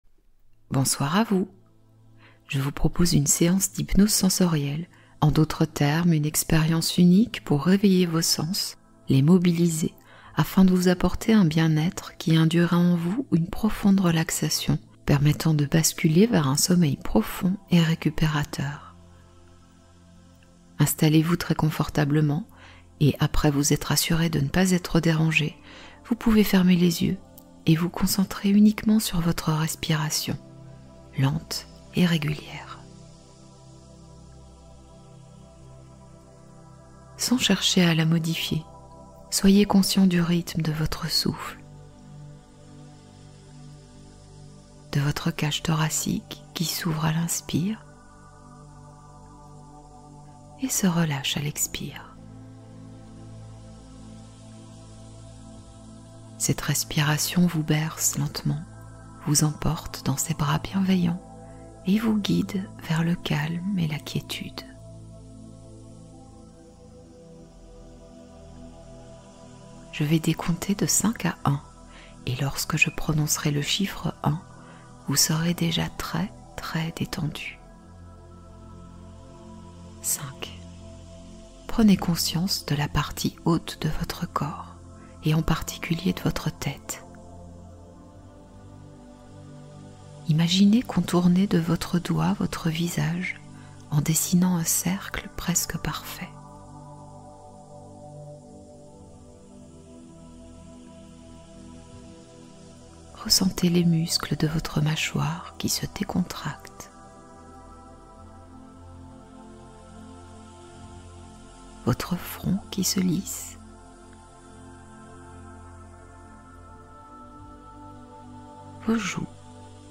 Hypnose minceur : sommeil guidé et soutien au changement